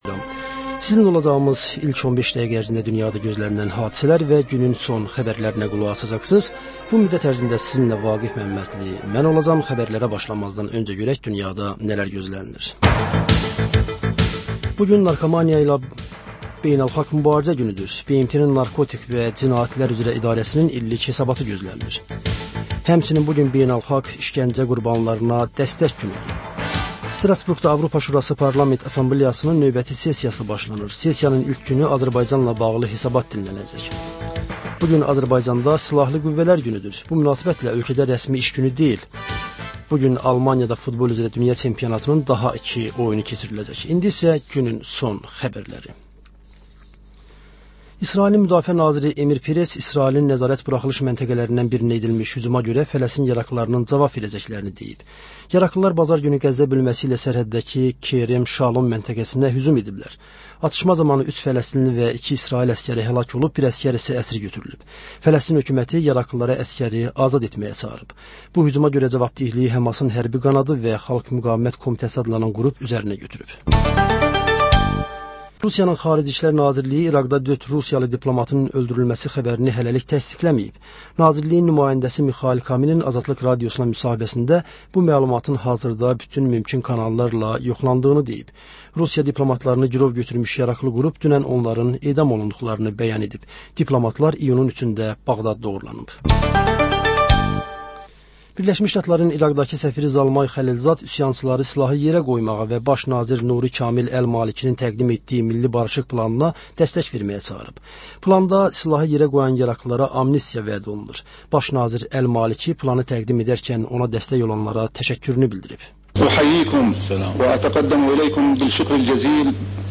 Səhər-səhər, Xəbər-ətər: xəbərlər, reportajlar, müsahibələr İZ: Mədəniyyət proqramı. Və: Tanınmışlar: Ölkənin tanınmış simalarıyla söhbət.